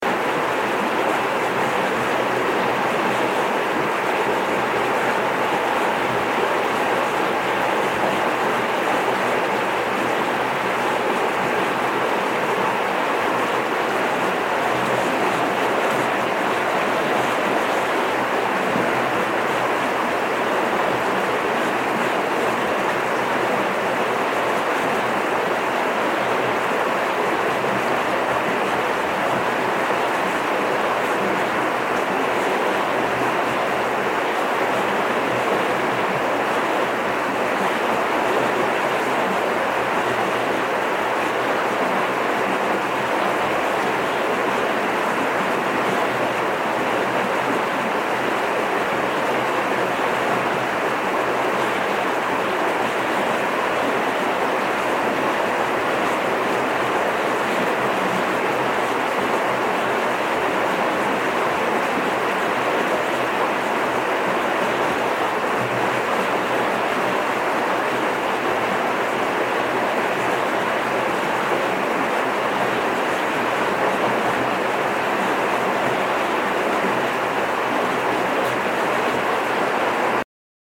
Audio - River
Nature Audio - No Distractions only Focus
river-mP4OJRjxG7SoK0y2.mp3